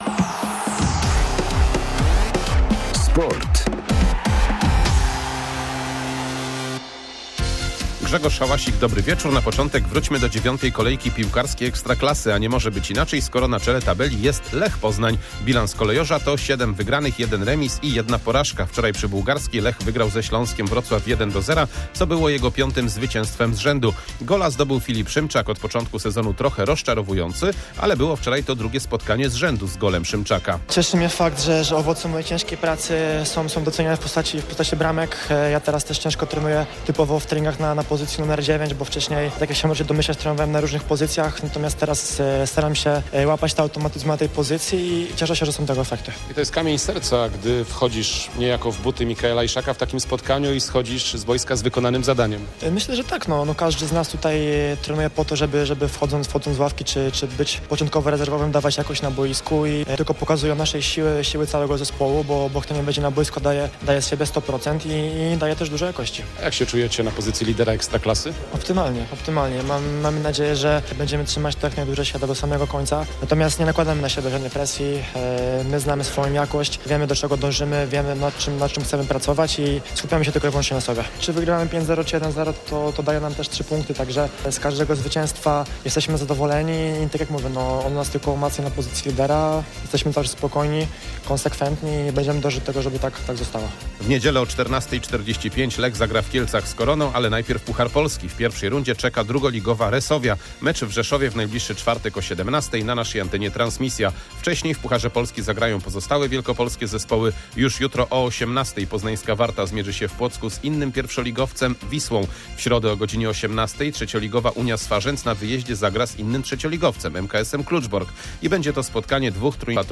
23.09.2024 SERWIS SPORTOWY GODZ. 19:05